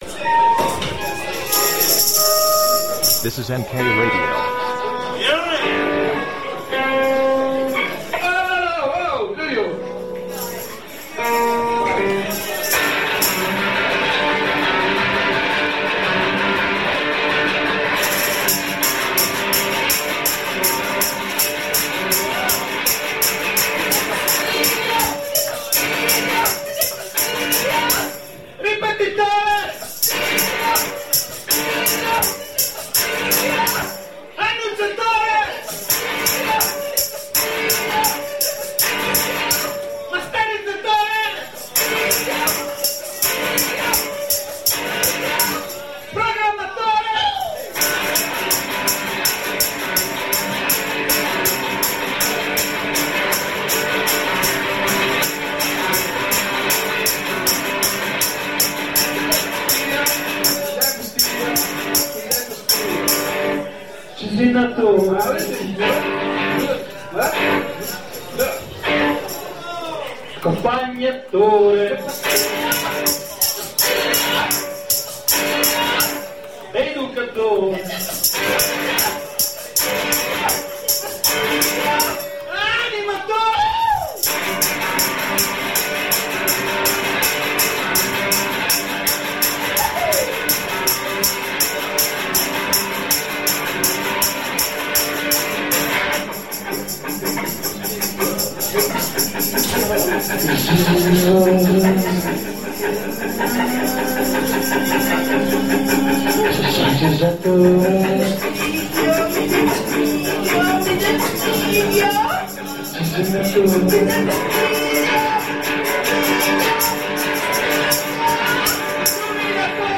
The SINK #86 – Live al CSA Spartaco (2 di 3) | Radio NK